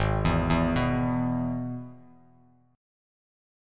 G#m#5 chord